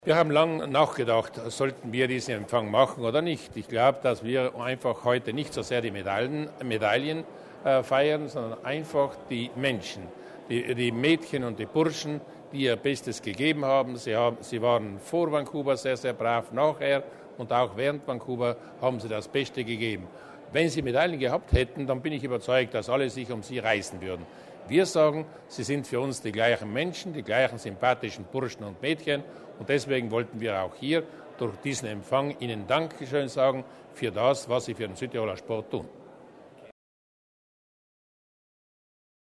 Die 45 Südtiroler Sportler, für die dieser Traum in Vancouver wahr geworden ist, hat Landeshauptmann Luis Durnwalder heute (9. April) Abend im Felsenkeller der Laimburg empfangen.